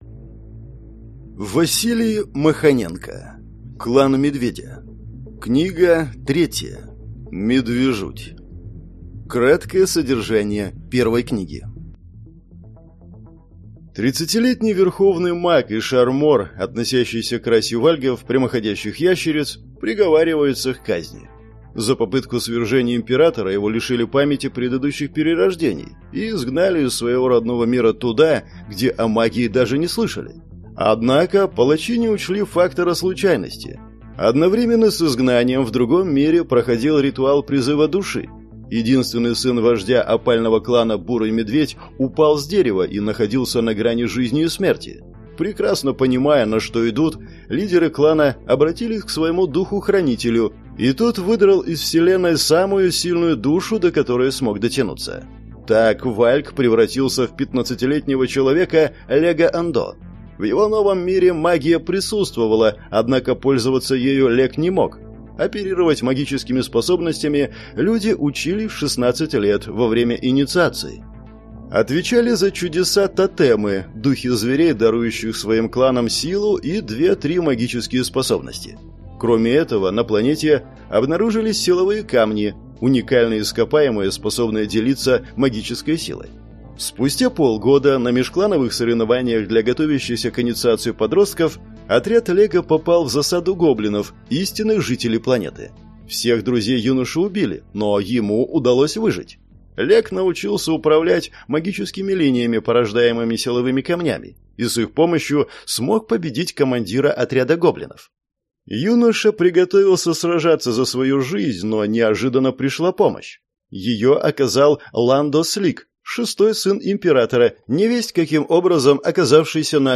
Аудиокнига Клан Медведя. Книга 3. Медвежуть | Библиотека аудиокниг